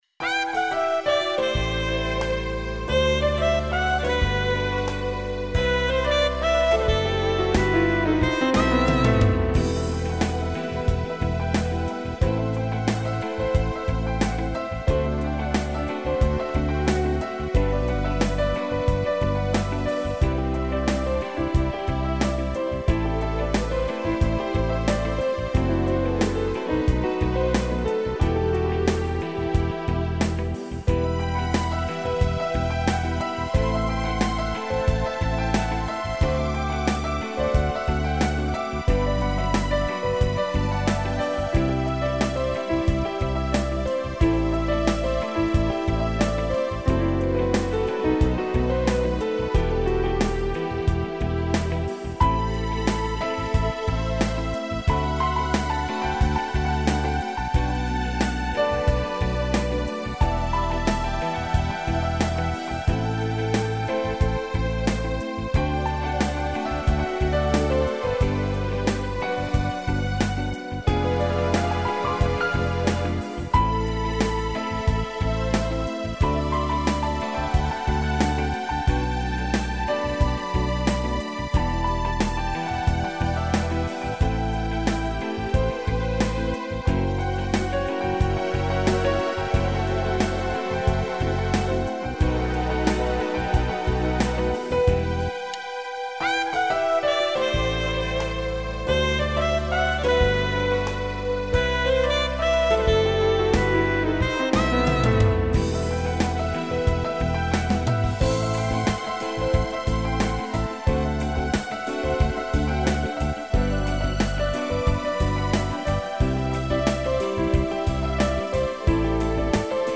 CD Instrumental